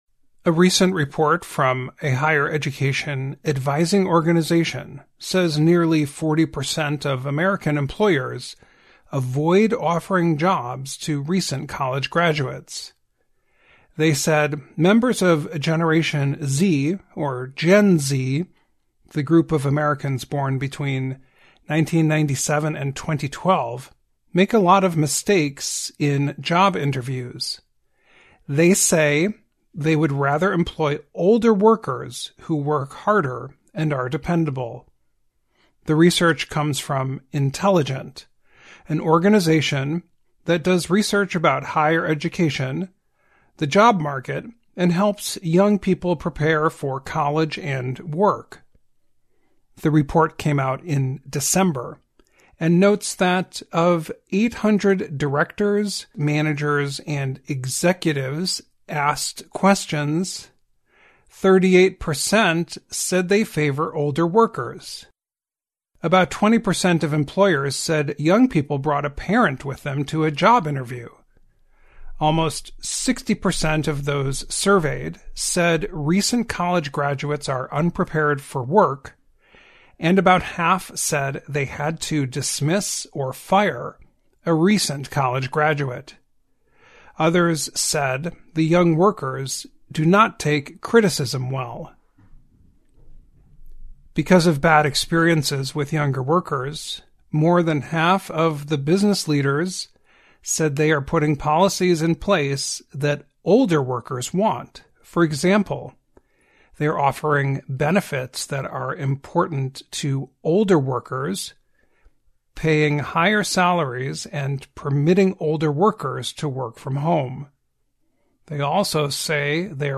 2024-01-21 [Education Report] US Businesses Concerned about Hiring Young, 'Gen Z' Workers
VOA慢速英语逐行复读精听提高英语听力水平